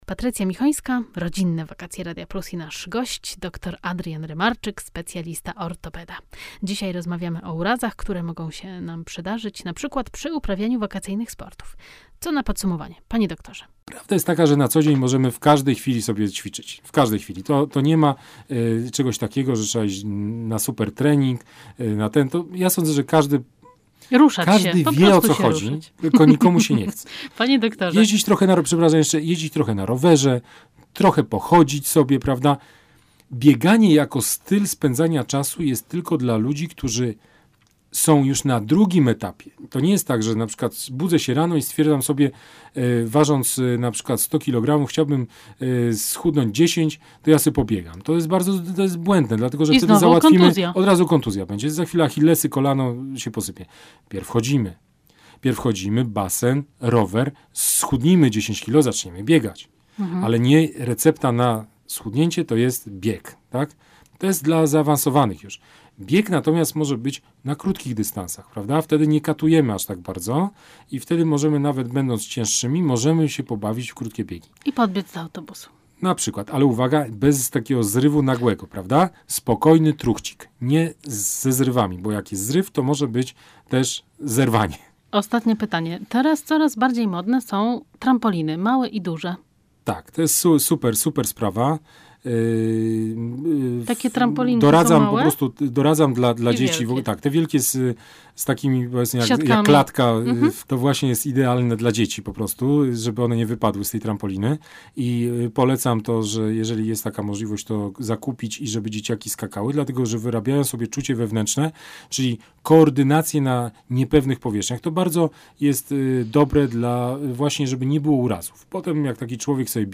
Wywiad z ortopedą w Radio Plus - Jedziemy na wakacje